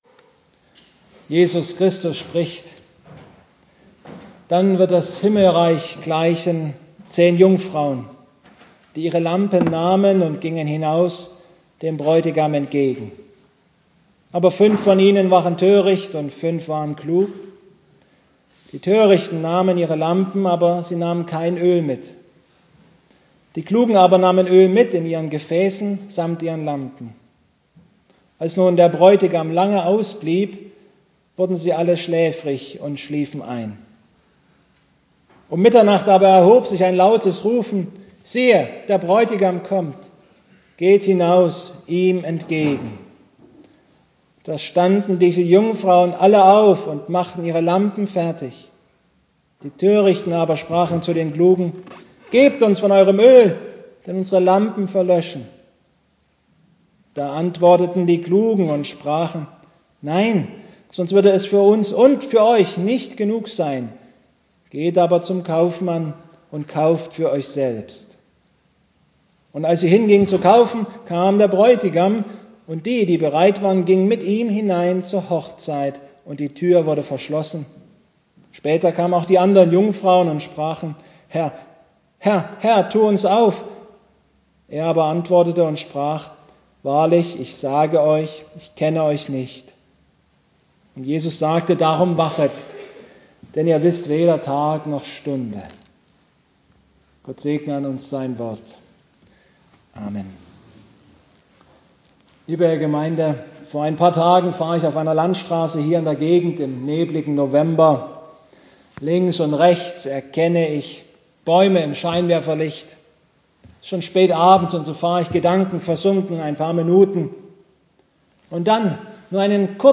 Mt 25,1-13 Gottesdienstart: Predigtgottesdienst Obercrinitz Wir feiern heute den Sonntag